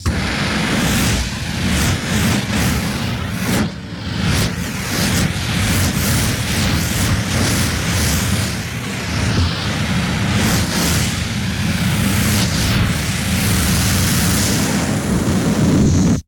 На этой странице собраны реалистичные звуки огнемета — от гула воспламенения до рева пламени.
Выстрел огнемётом — выжигаем всё